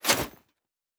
pgs/Assets/Audio/Fantasy Interface Sounds/Foley Armour 07.wav at master
Foley Armour 07.wav